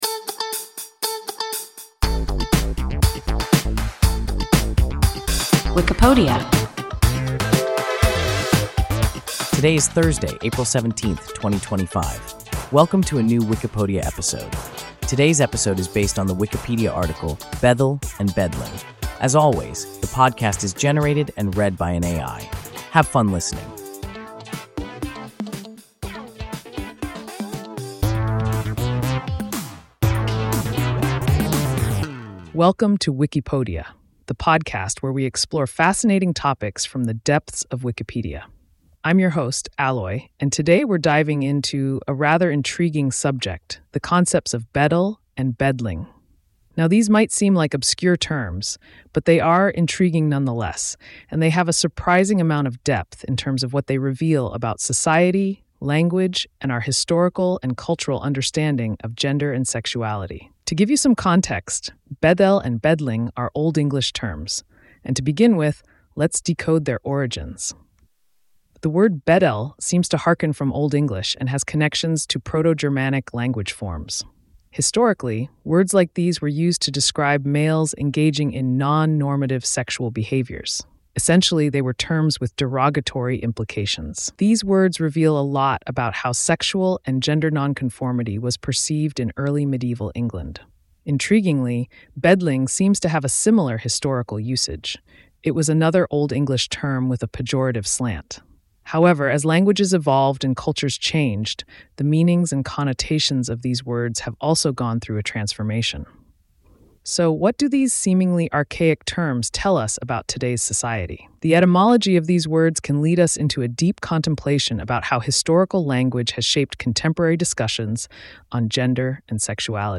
Bæddel and bædling – WIKIPODIA – ein KI Podcast